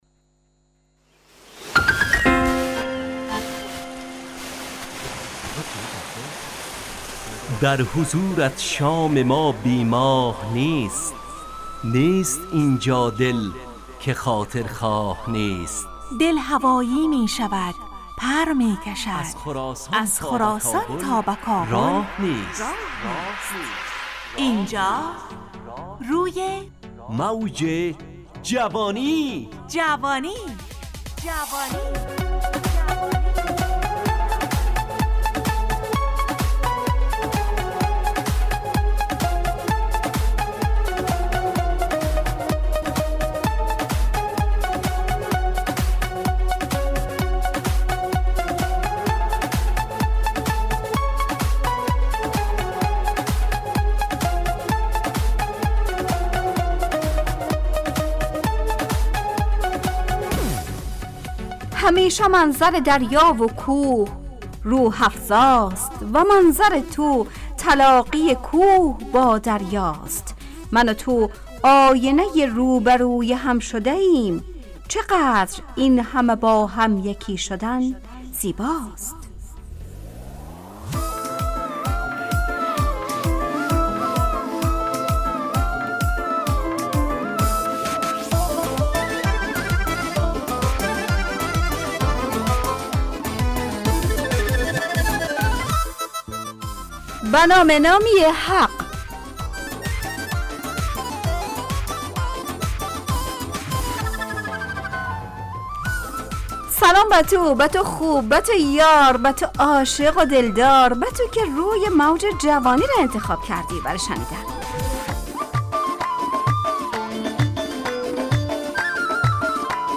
روی موج جوانی، برنامه شادو عصرانه رادیودری.
همراه با ترانه و موسیقی مدت برنامه 70 دقیقه . بحث محوری این هفته (سفر) تهیه کننده